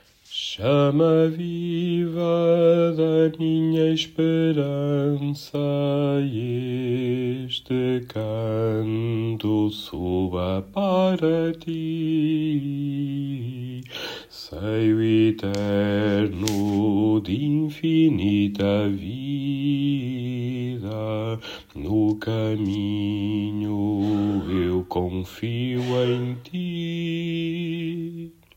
Baixo